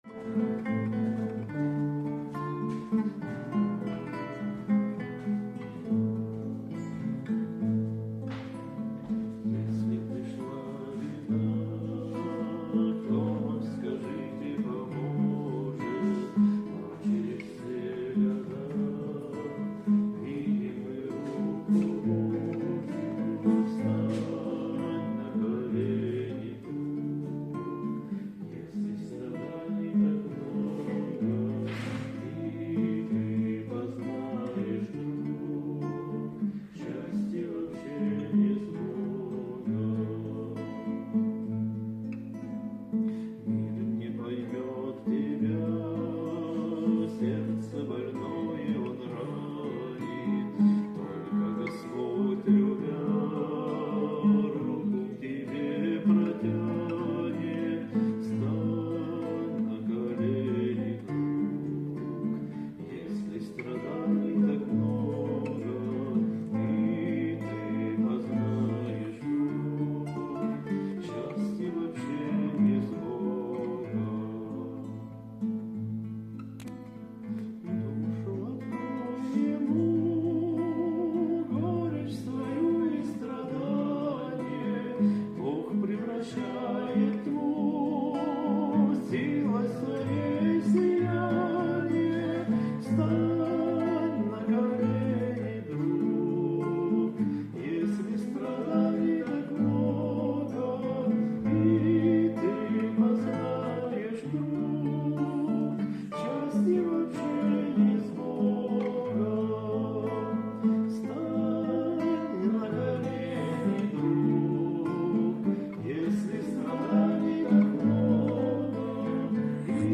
88 просмотров 217 прослушиваний 2 скачивания BPM: 106